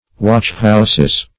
Watchhouses synonyms, pronunciation, spelling and more from Free Dictionary.
watchhouses.mp3